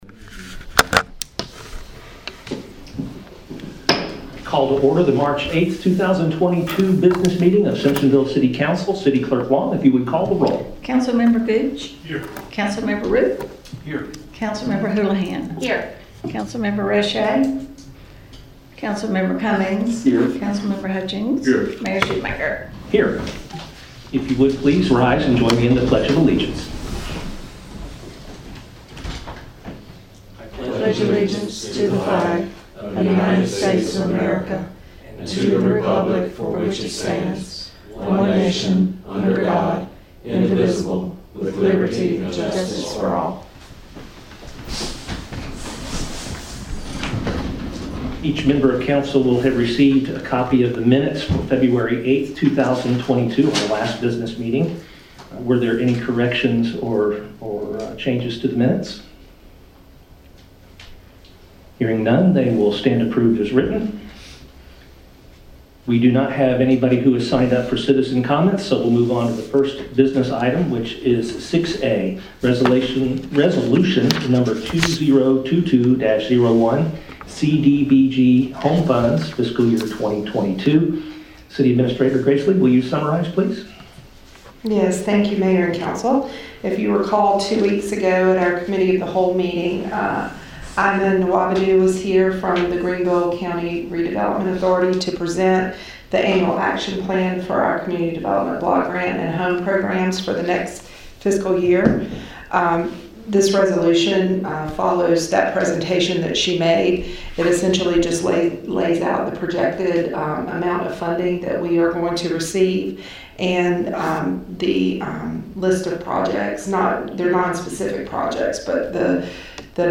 City Council Business Meeting
Simpsonville City Council will hold a regularly scheduled business meeting March 8 at 6:30 p.m. in Council Chambers at City Hall.